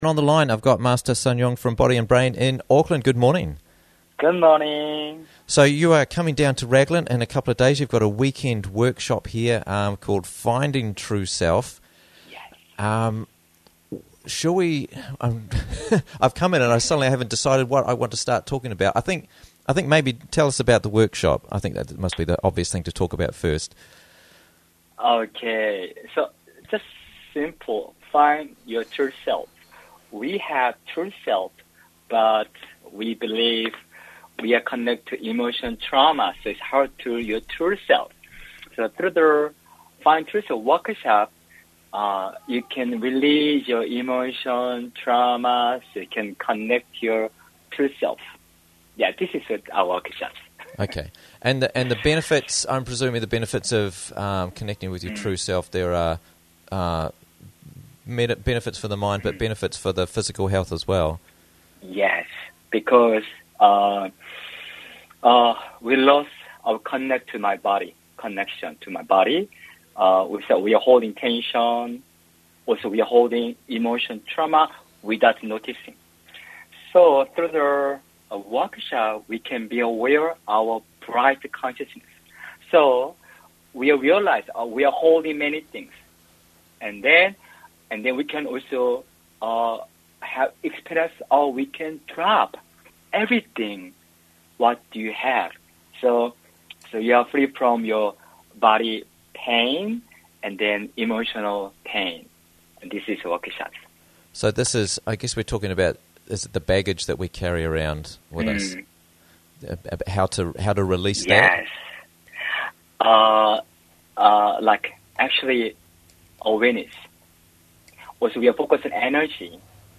Finding True Self Workshop This Weekend - Interviews from the Raglan Morning Show